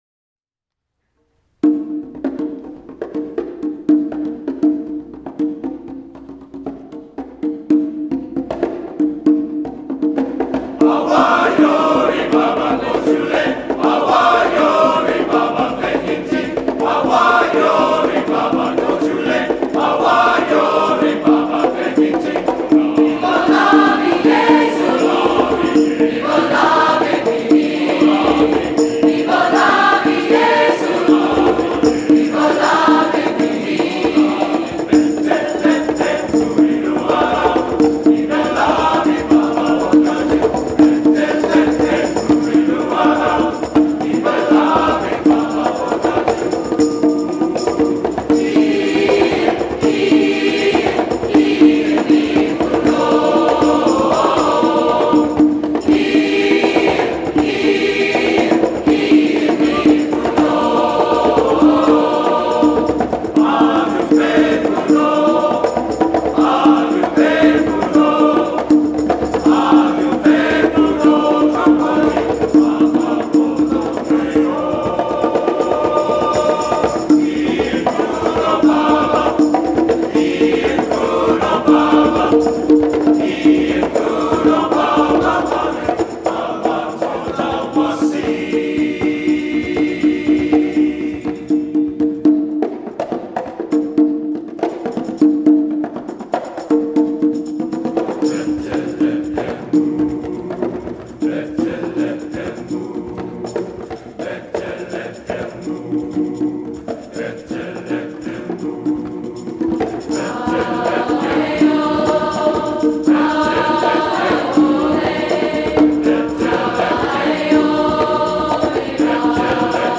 SINGERS